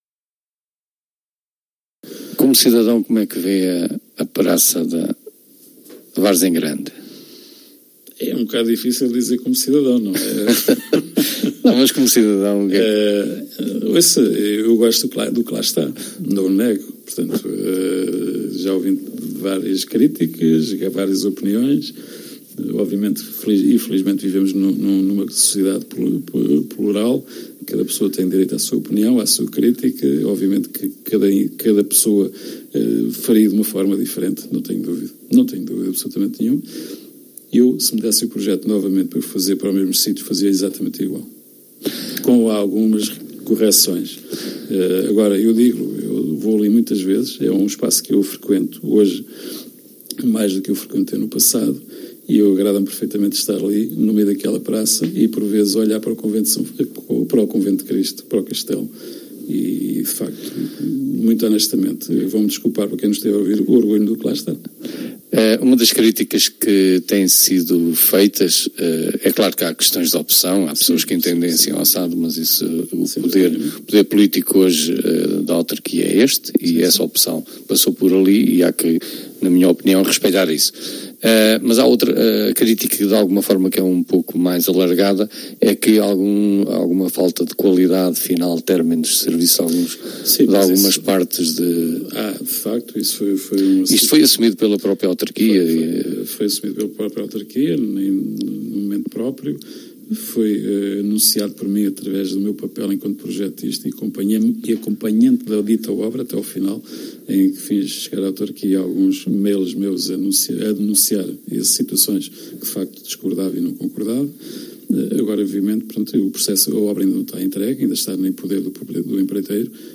A entrevista completa